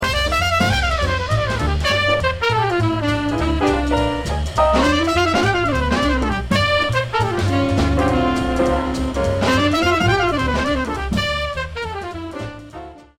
on alto sax